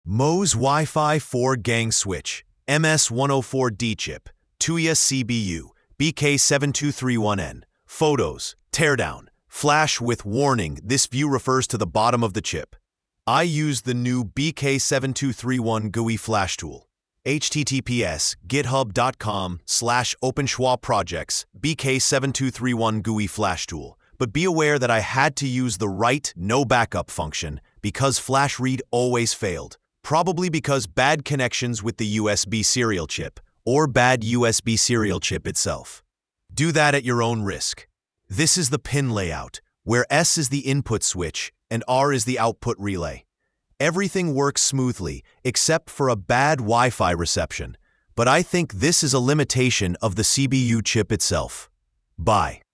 📢 Listen (AI):